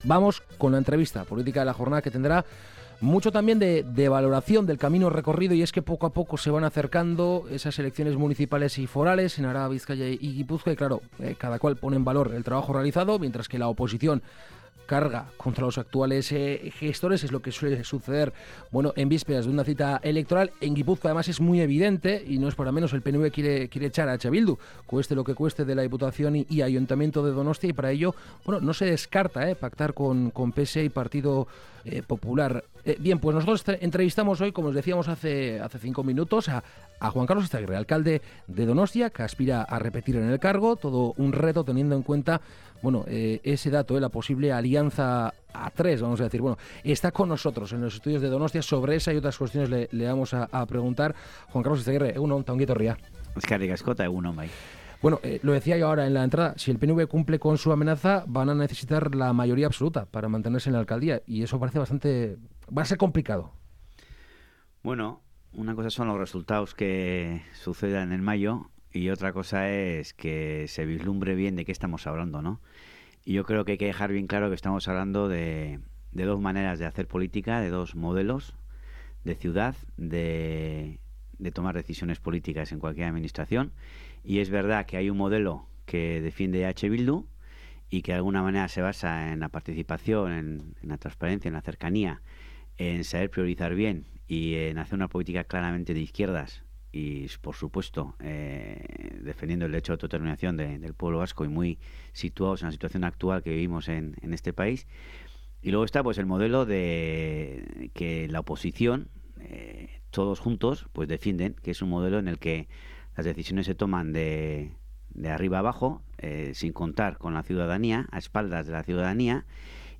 Hoy en Kalegorrian hemos entrevistado a Juan Karlos Izagirre, alcalde de Donostia [EH Bildu] con el que hems echo un recorrido a lo largo de los cuatro años de mandato en el ayuntamiento donostiarra. Izagirre ha reflexionado sobre participación ciudadana, crísis económica y alternativas sociales o en torno a la aportación de su Gobierno municipal a la reconcialiación.